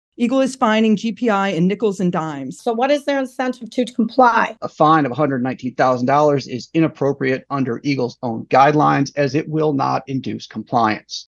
KALAMAZOO, MI (WKZO AM/FM) – A public hearing on a consent agreement for modifications at Graphic Packaging turned into a protest against the paperboard recycler and state environment officials last night.
Anger and frustration was expressed that the state agency won’t or can’t do more, and they charge the company seems indifferent.